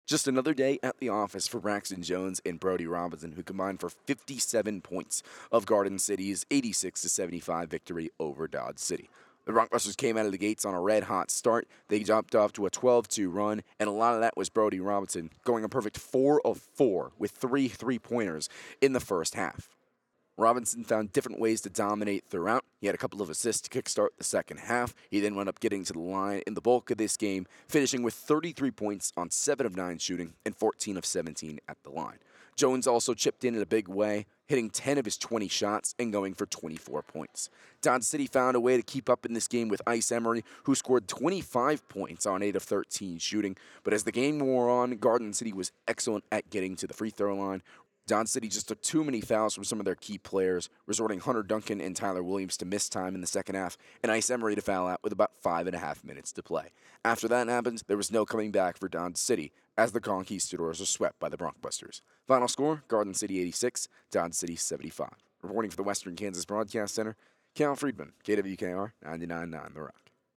Audio Recap